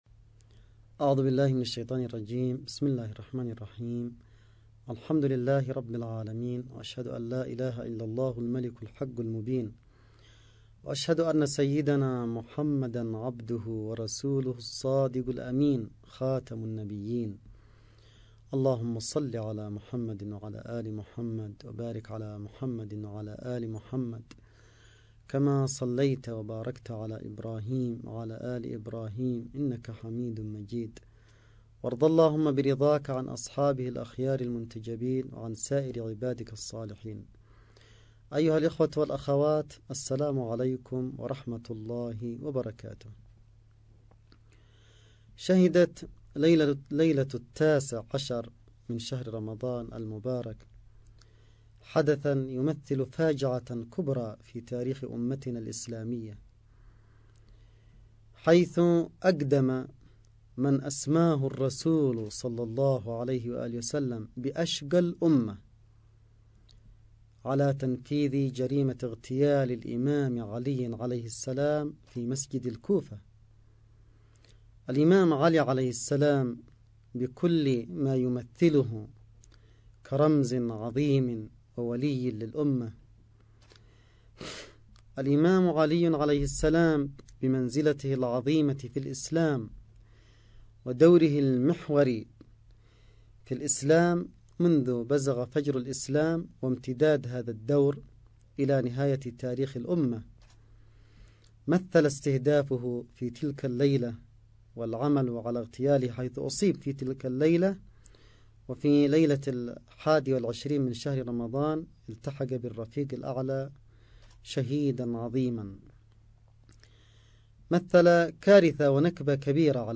خطابات القائد